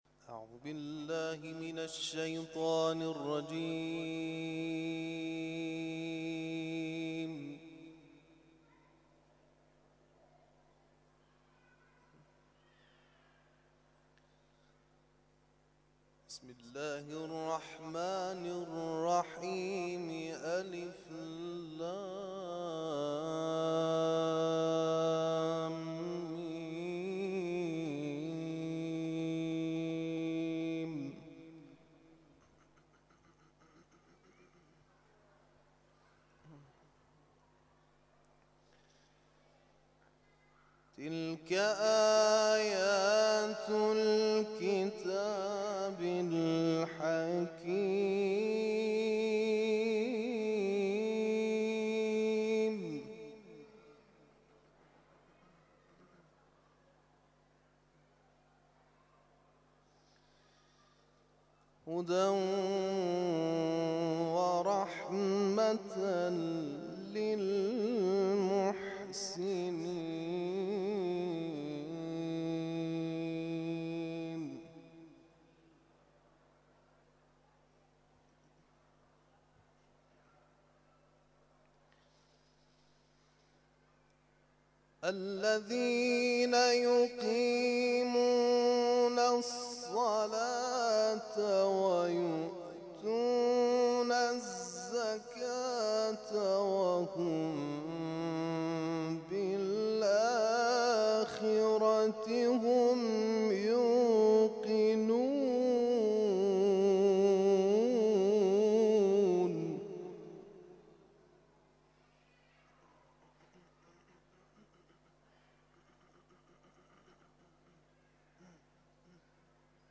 تلاوت مغرب